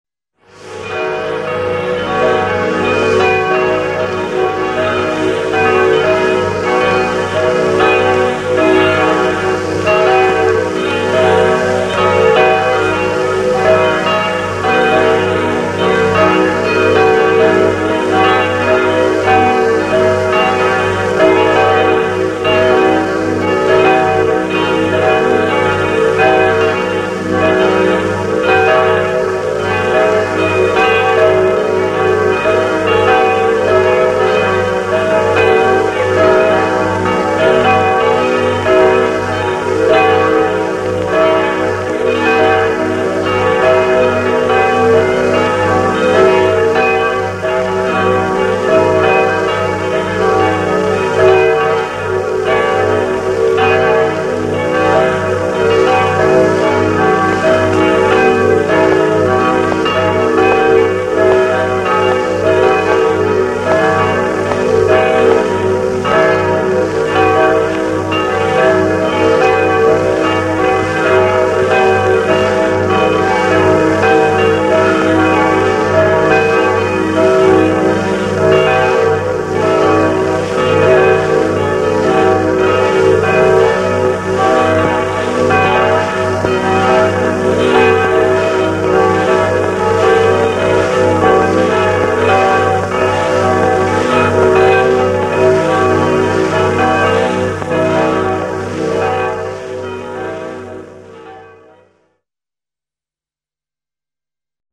Geläut der Roisdorfer Pfarrkirche am 11. Februar 1942 [1.640 KB]
gelaeut.mp3